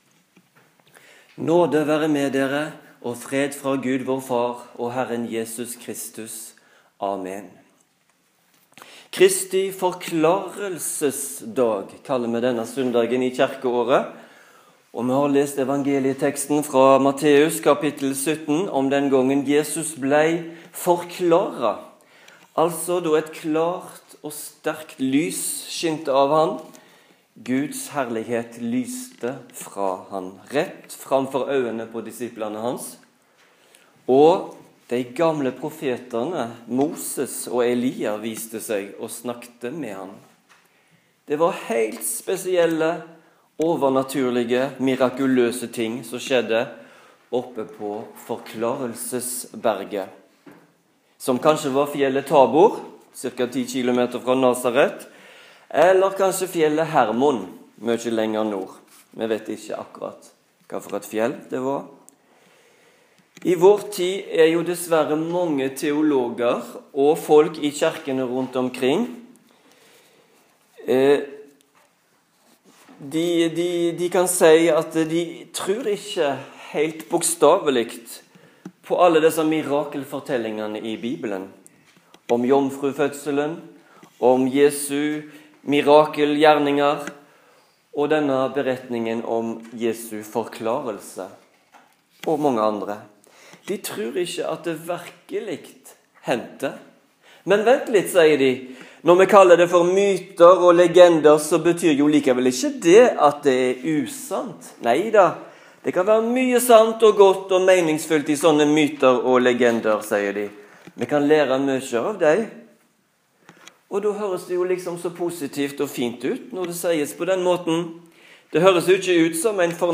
Preken på Kristi forklarelsesdag